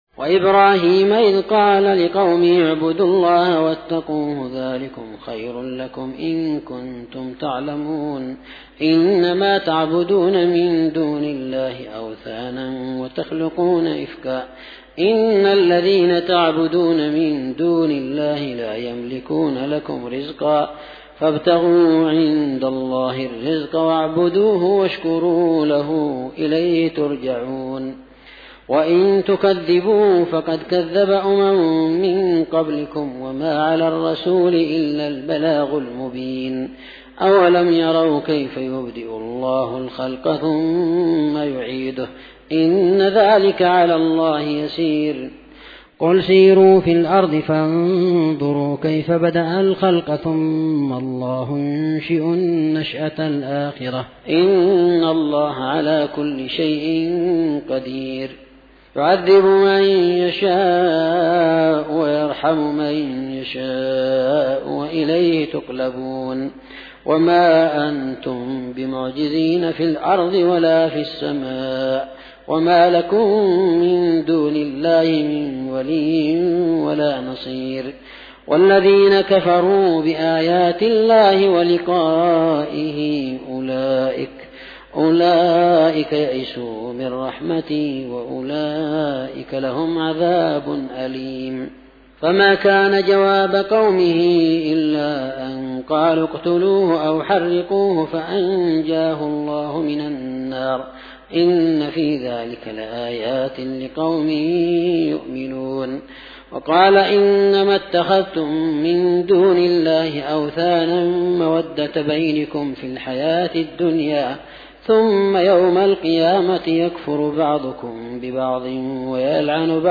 Tilawah Ramadhan 1433H Darul Hadits Dammaj
{مقتطفات من صلاة القيام}